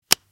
typing.mp3